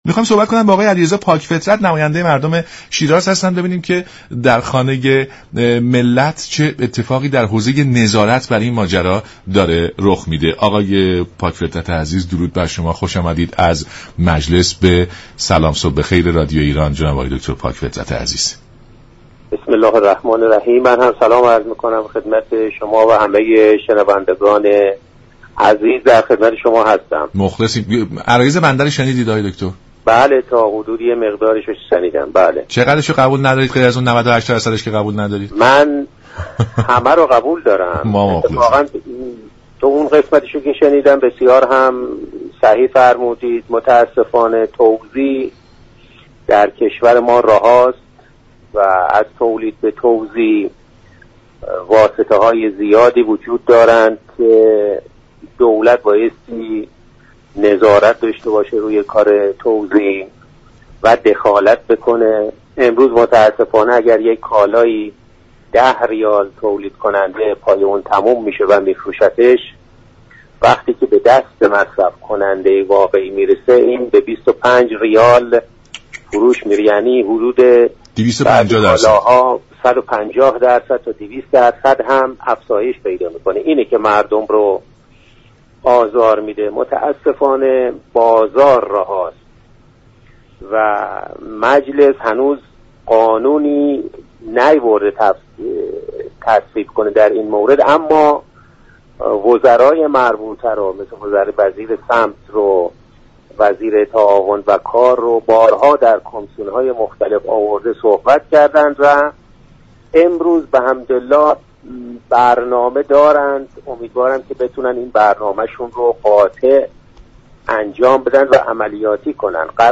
به گزارش شبكه رادیویی ایران، «علیرضا پاك فطرت» نماینده مردم شیراز در مجلس شورای اسلامی، در برنامه «سلام صبح بخیر» به گرانی لوازم خانگی و وضعیت نظارت بر آن پرداخت و گفت: سیستم توزیع در كشور رهاست، لازم است دولت نظارت و دخالت بیشتری بر آن داشته باشد.